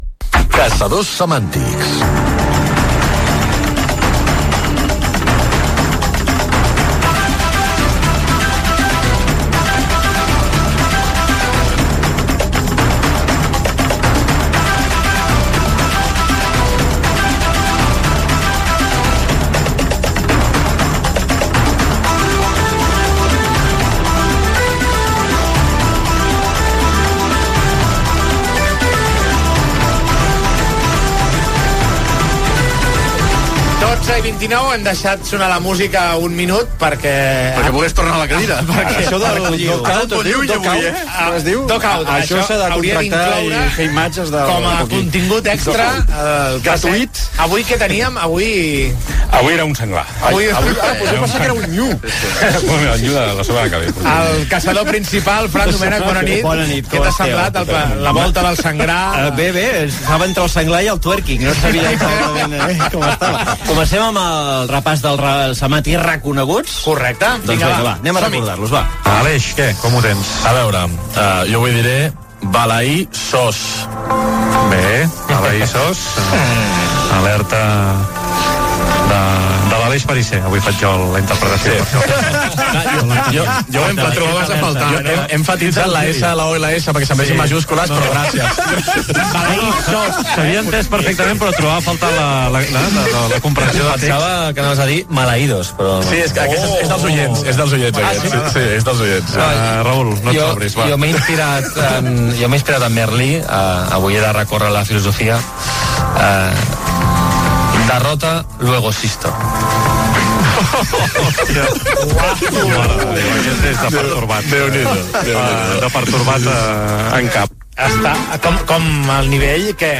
Secció humorística
Gènere radiofònic Esportiu